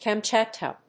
音節Kam・chat・ka 発音記号・読み方
/kæmtʃˈætkə(米国英語), ˌkɑ:ˈmtʃɑ:tkʌ(英国英語)/